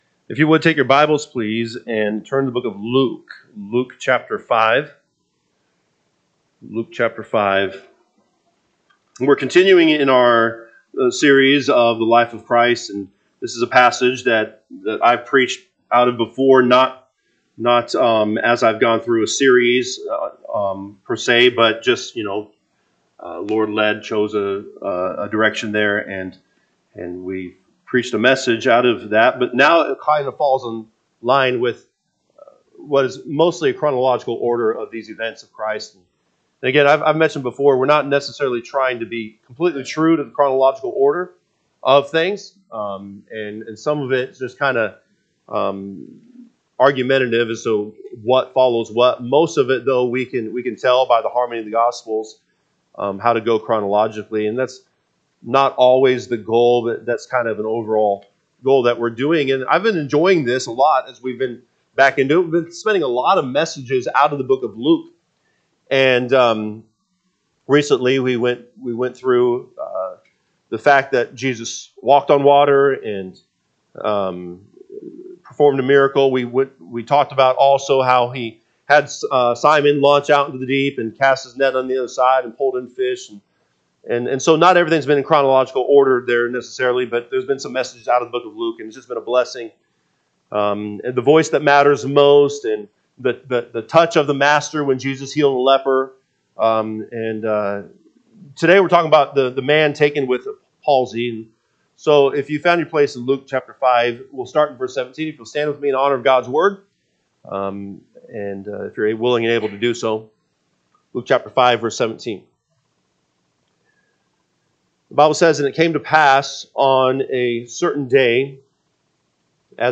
September 14, 2025 am Service Luke 5:17-26 (KJB) 17 And it came to pass on a certain day, as he was teaching, that there were Pharisees and doctors of the law sitting by, which were come out o…
Sunday AM Message